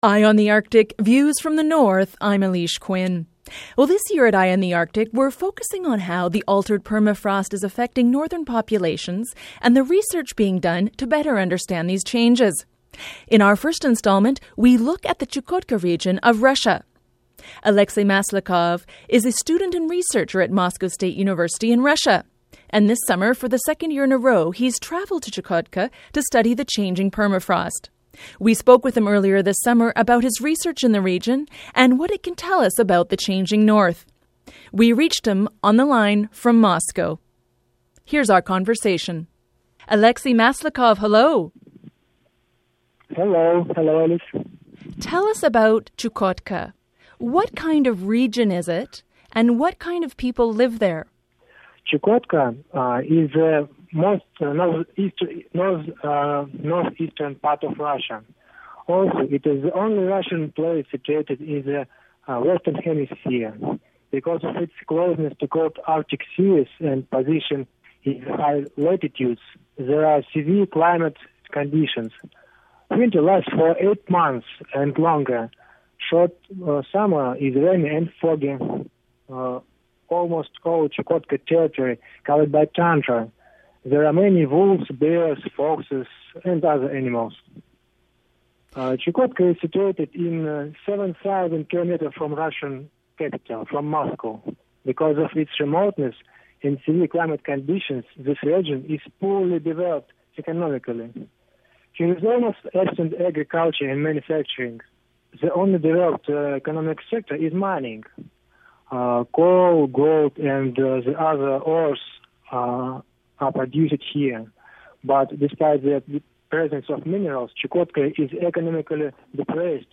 We reached him on the line from Moscow.